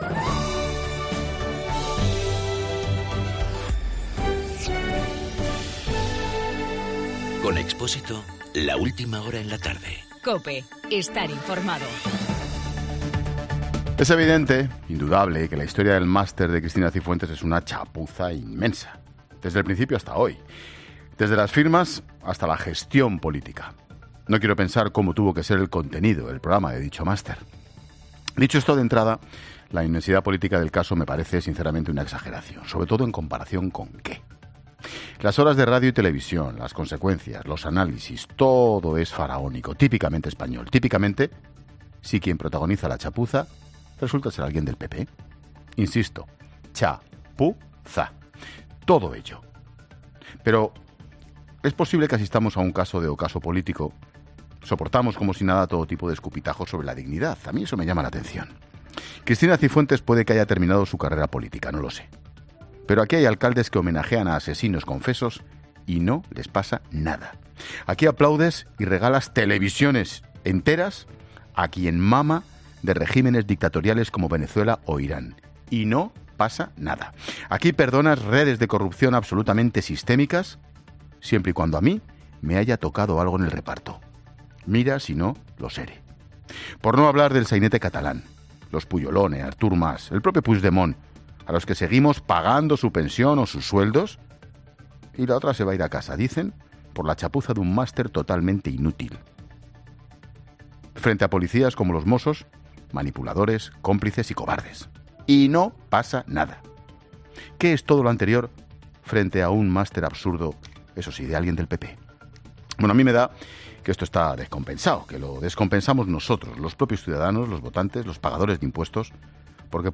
Monólogo de Expósito
El comentario de Ángel Expósito.